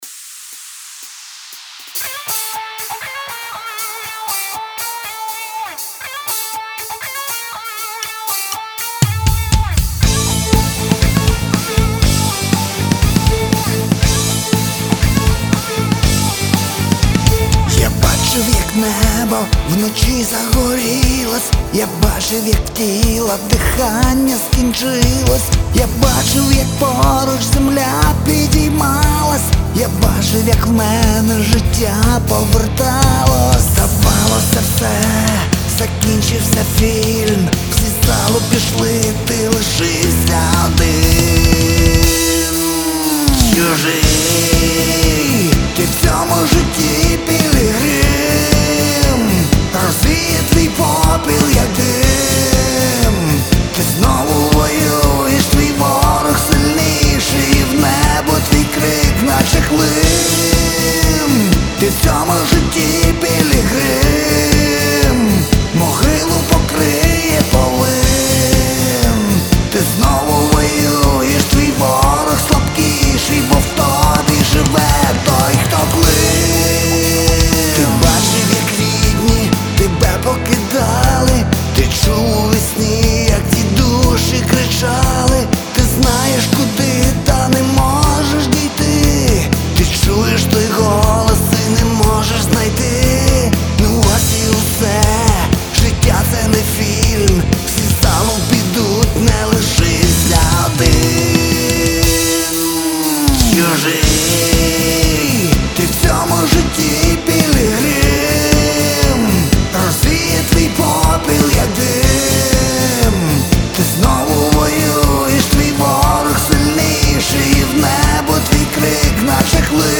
80 просмотров 101 прослушиваний 0 скачиваний BPM: 120